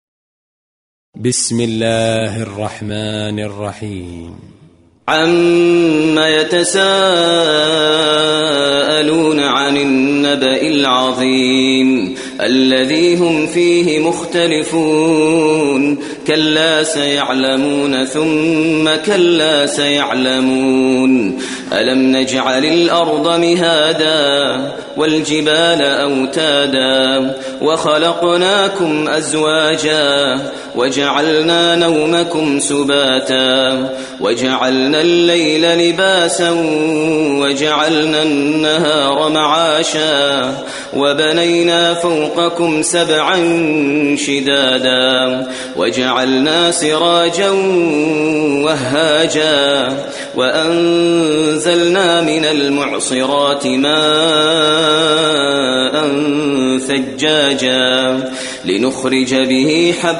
Coran